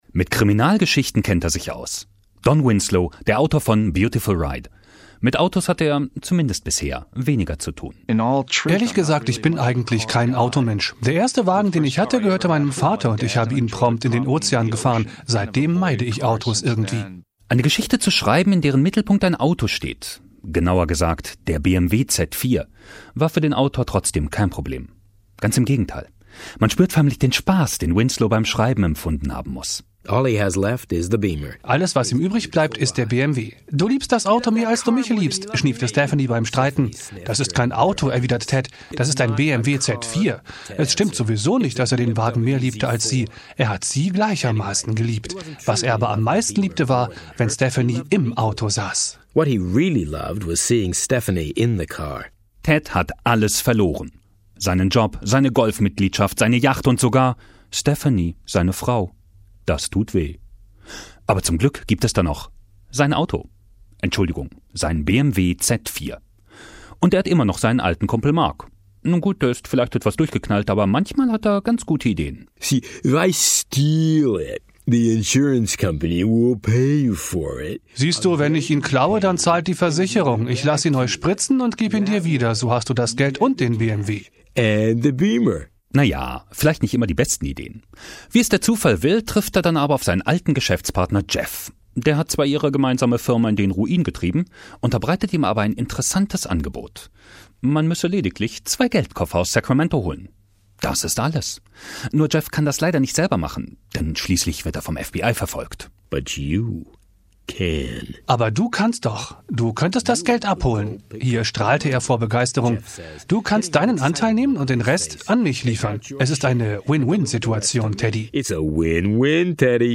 BMW Audio Book.